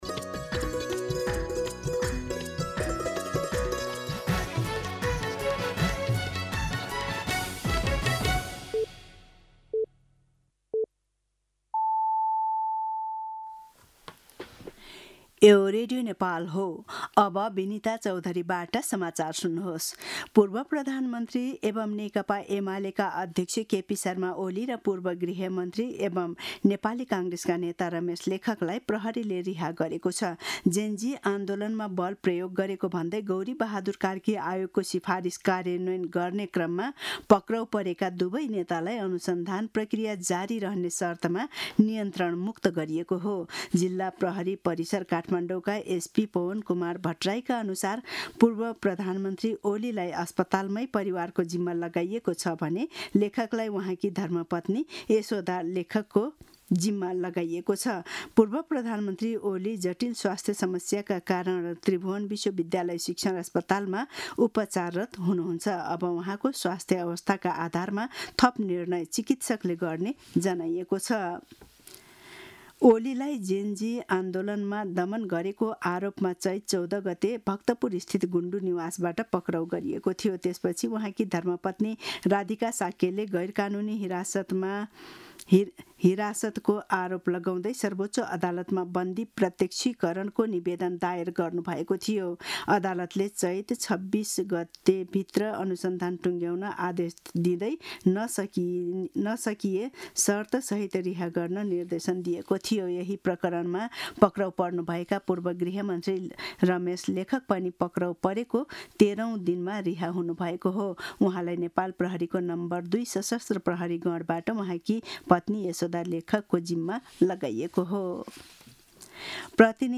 दिउँसो १ बजेको नेपाली समाचार : २६ चैत , २०८२